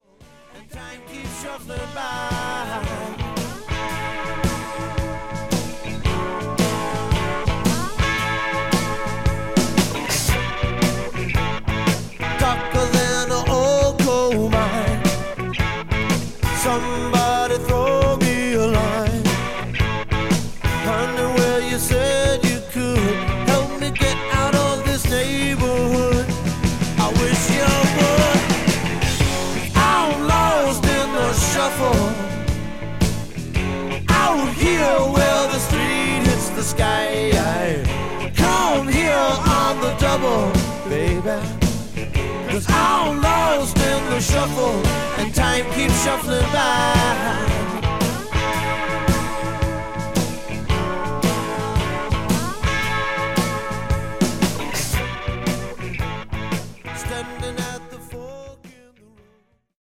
ハード・ロックやニューウェイヴなどの影響を取り込んだ’80年代らしいソリッドな音像で聴かせるロック・アルバムです。